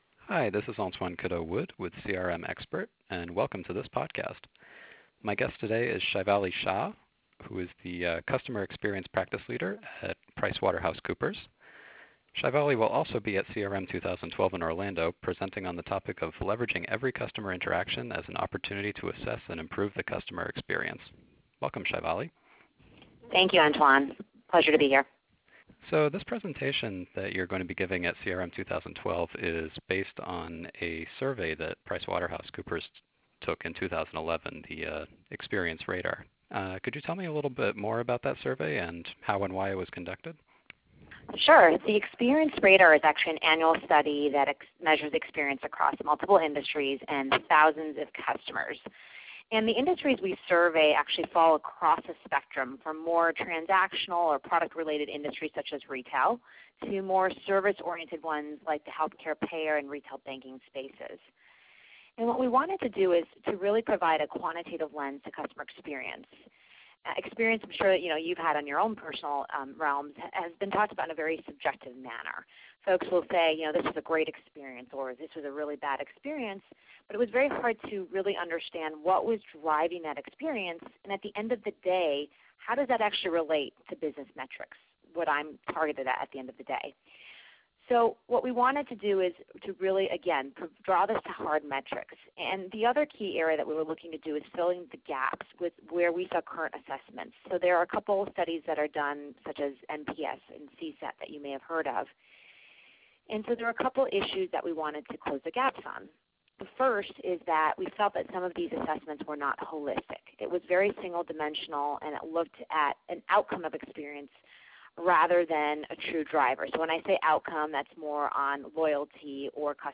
Surprising Insights on Leveraging Customer Experience: An Interview